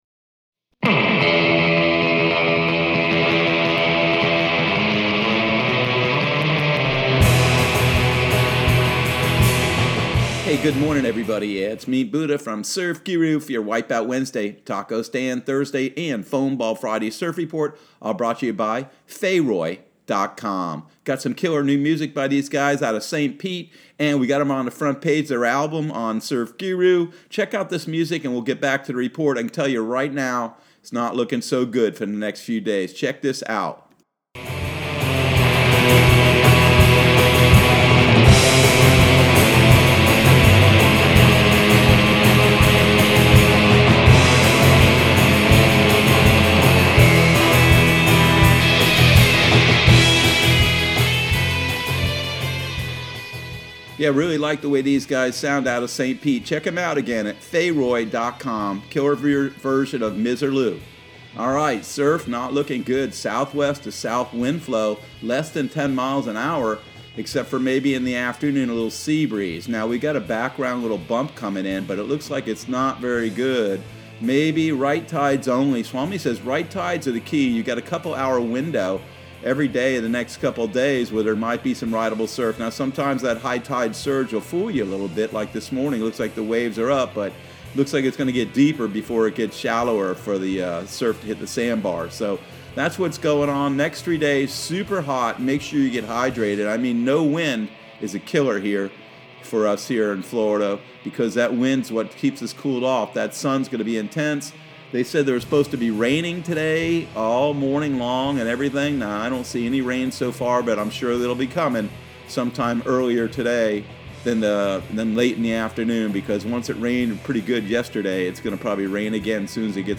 Surf Guru Surf Report and Forecast 08/05/2020 Audio surf report and surf forecast on August 05 for Central Florida and the Southeast.